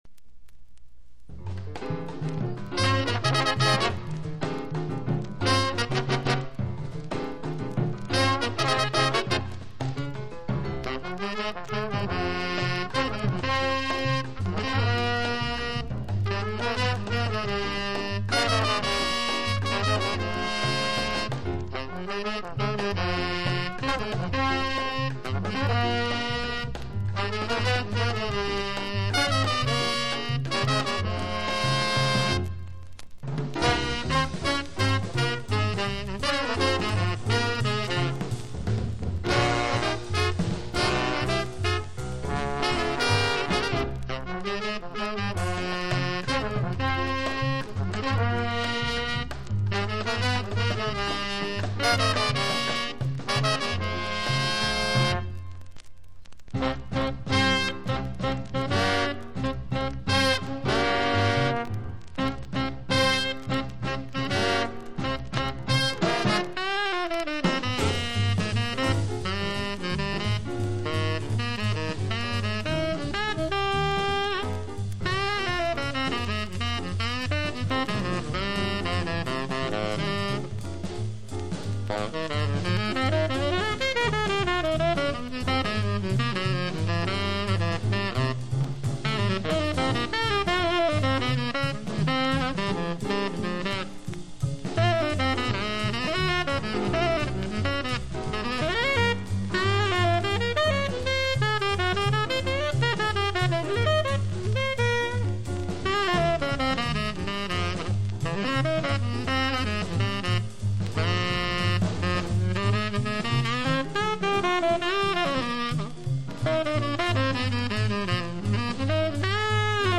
（プレス・小傷によりチリ、プチ音ある曲あり）
Genre US JAZZ